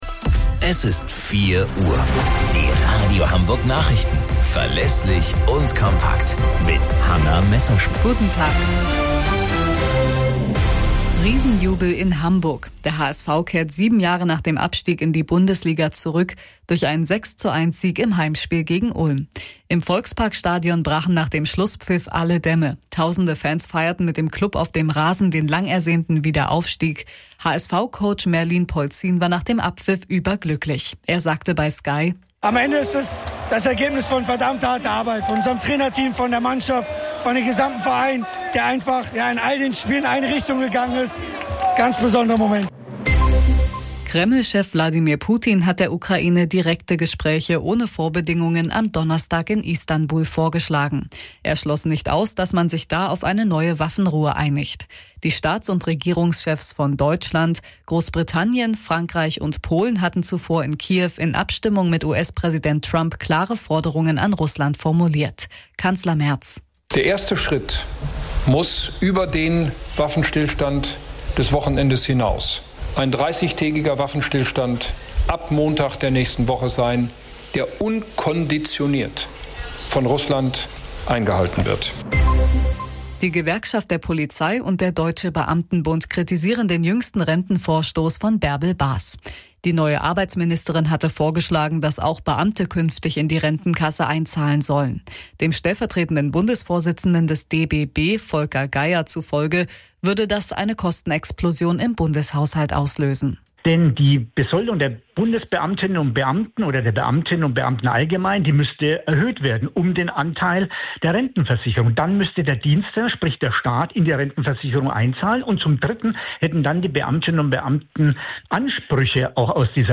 Radio Hamburg Nachrichten vom 11.05.2025 um 11 Uhr - 11.05.2025